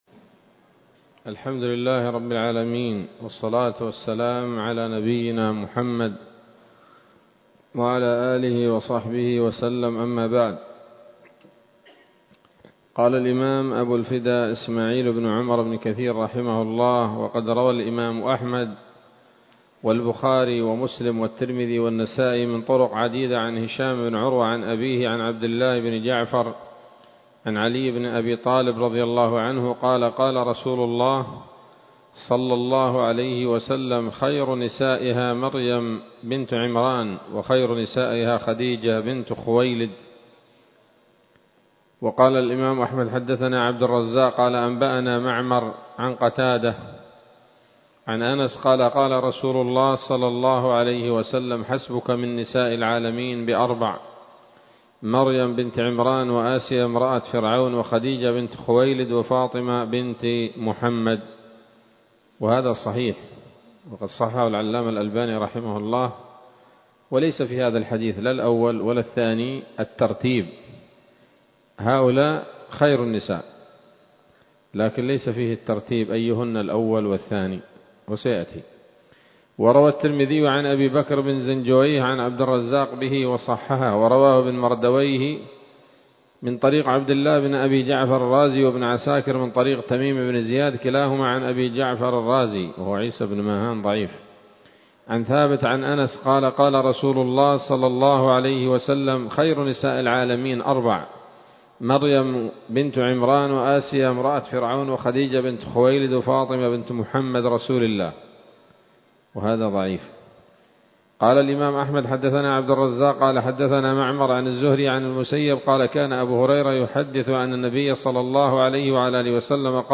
‌‌الدرس التاسع والثلاثون بعد المائة من قصص الأنبياء لابن كثير رحمه الله تعالى